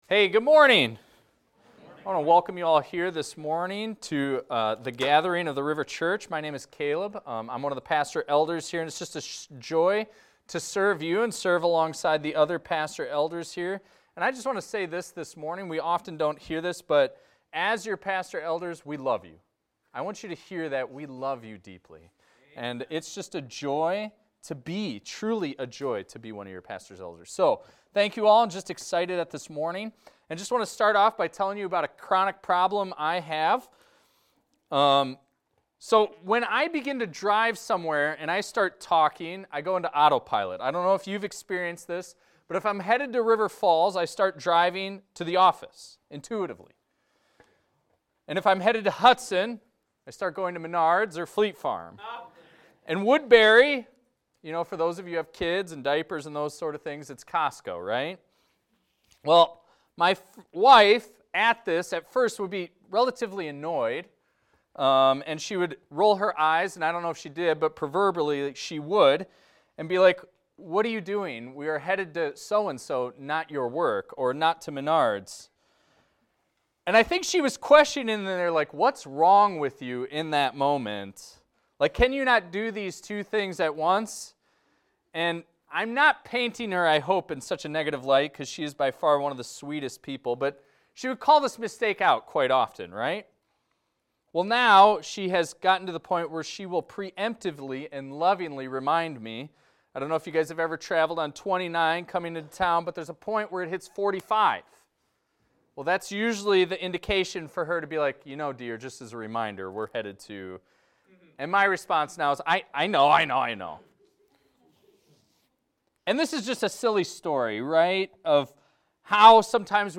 This is a recording of a sermon titled, "Not Being A Blessing."